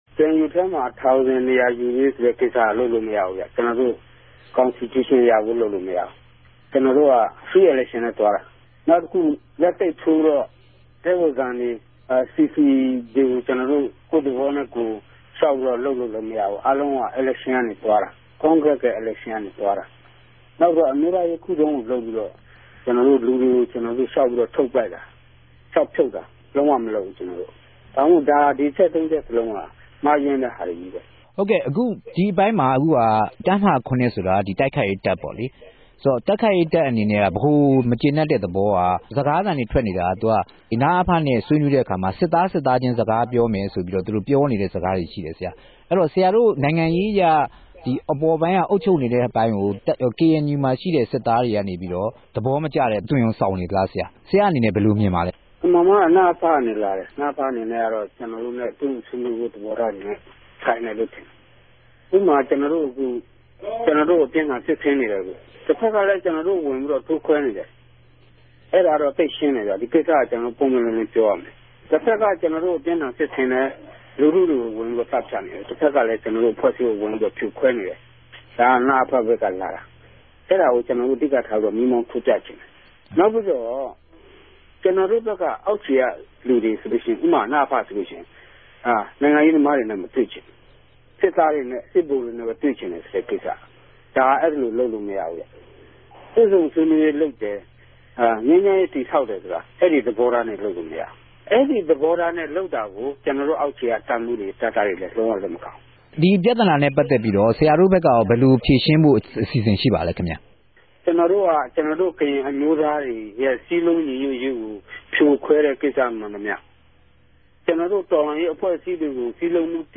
ဒီကိင်္စနဲႛပတ်သက်လိုႛ KNU အတြင်းရေးမြြးအပၝအဝင် တပ်မဟာ ၇က အရာရြိတခဵိြႚကို RFAက မေးူမန်းခဲ့ပၝတယ်။ KNU အထြေထြေ အတြင်းရေးမြြး ဖဒိုမန်းရြာကို ပထမဆုံး မေးူမန်းတဲ့အခၝမြ တပ်မဟာ ၇ရဲ့ စြပ်စြဲခဵက်တေကြို ူငင်းဆိုခဲ့ပၝတယ်။